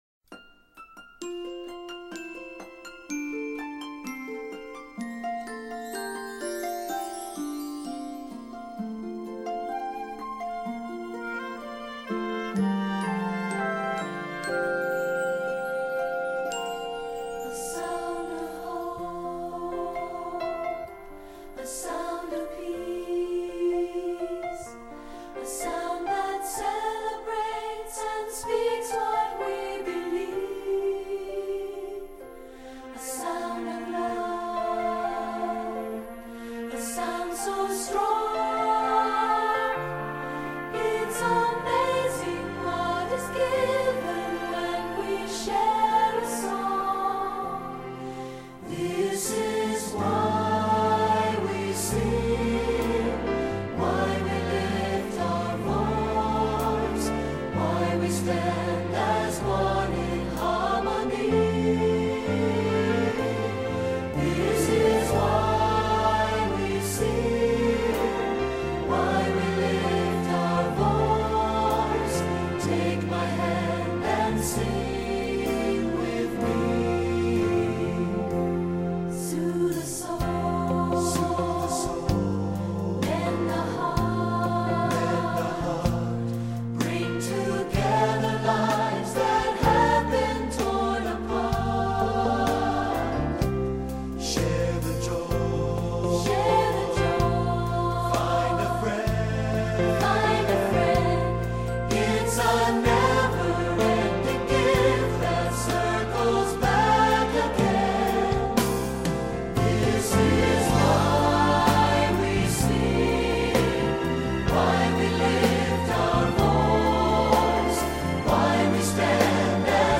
Choral Concert/General Graduation/Inspirational
Outstanding choral work.
SATB